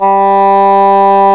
WND ENGHRN0A.wav